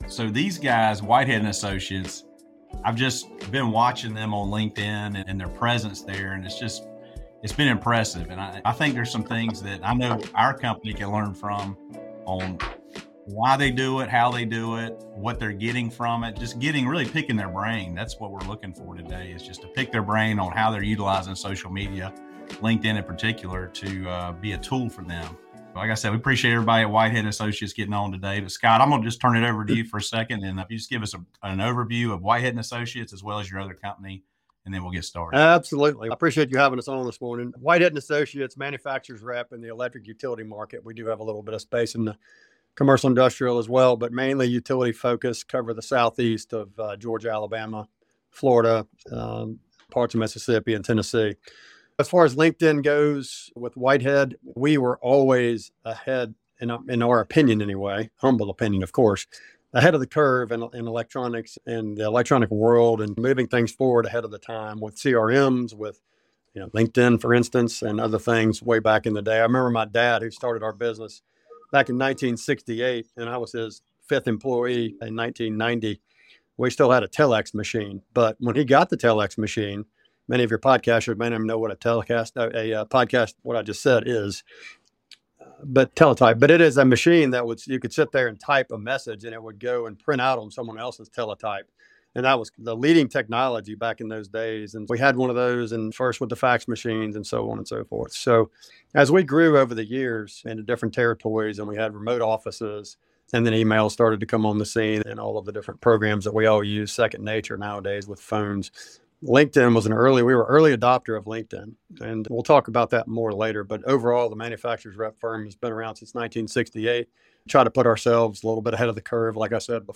Welcome to "Flowing Sales," the interview podcast for manufacturing sales reps and manufacturing leaders focused on sales growth.